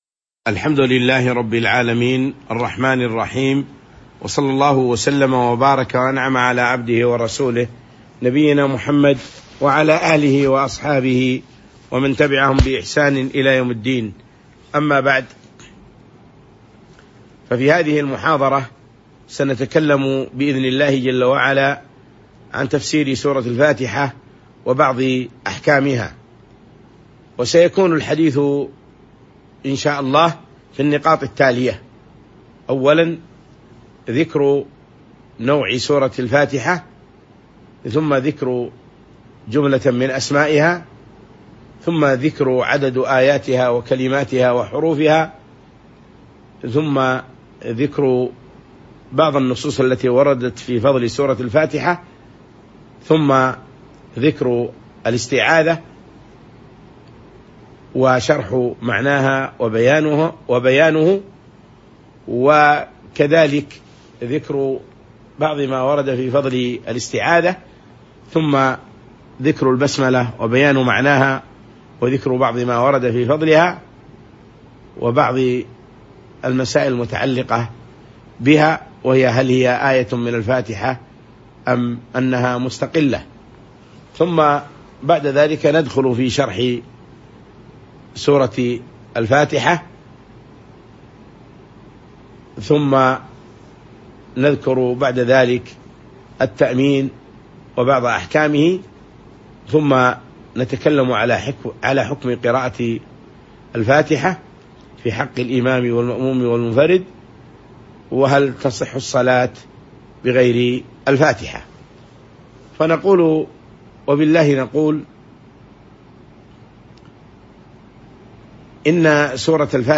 تاريخ النشر ٢٠ ذو الحجة ١٤٤٥ هـ المكان: المسجد النبوي الشيخ